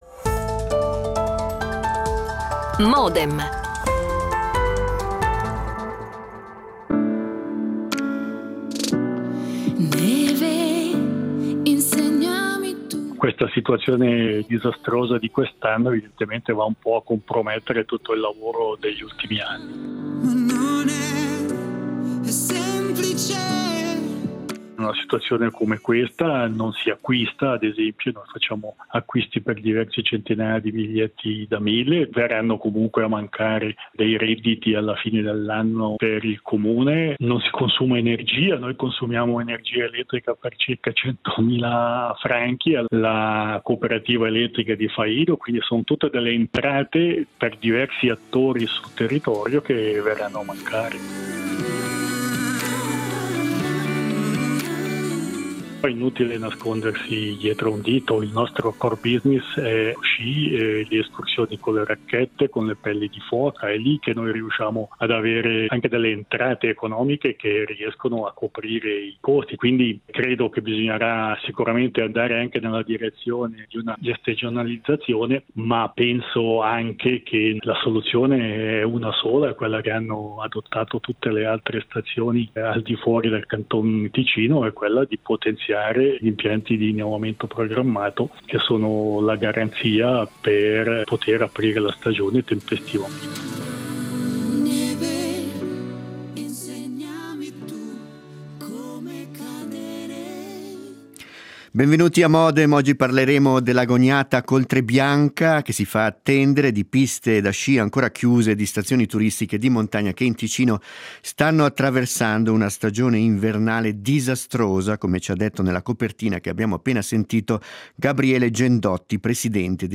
La puntata inizia con un reportage
L'attualità approfondita, in diretta, tutte le mattine, da lunedì a venerdì